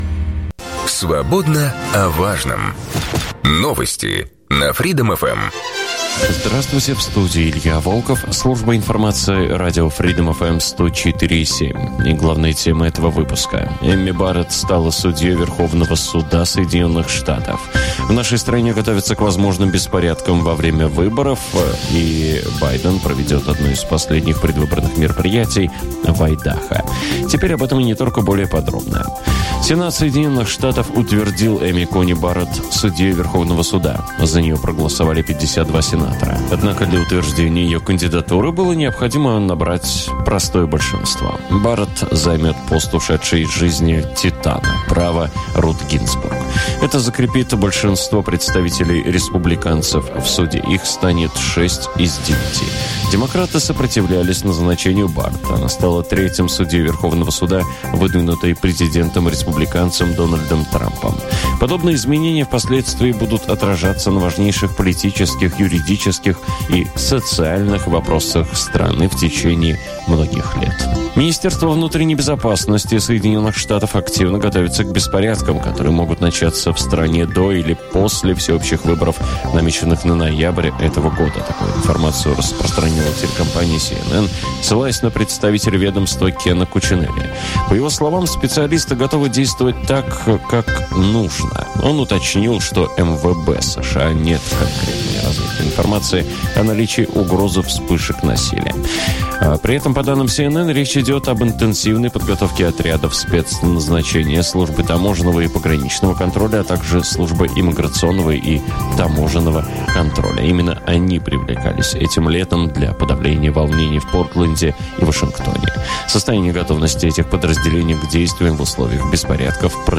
Радио-экскурсии "О. май гид!" на FreedomFM.